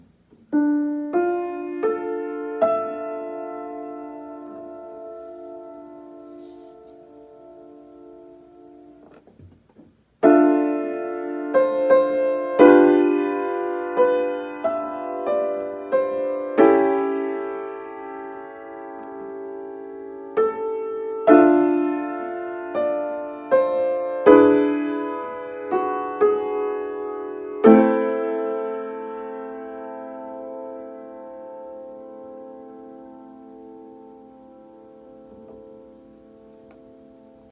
Key written in: D Minor
How many parts: 4
Type: Barbershop
All Parts mix: